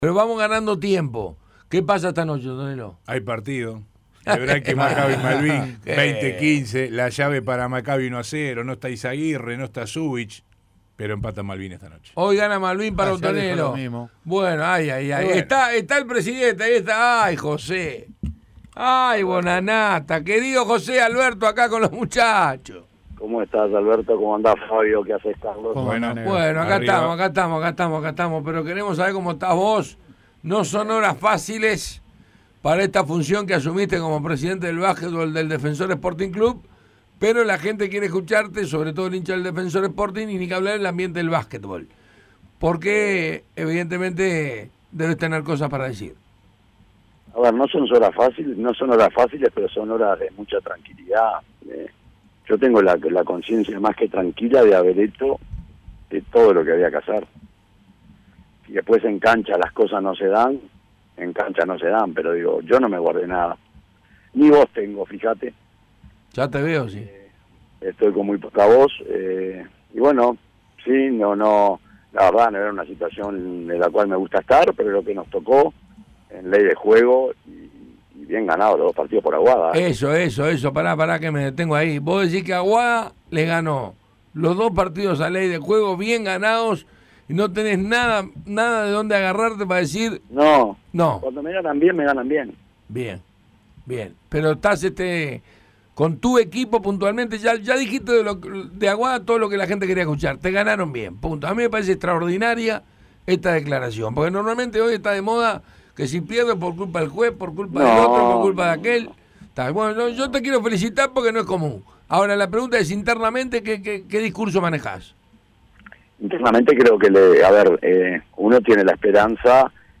Como de costumbre, no se guardó nada. Entrevista completa.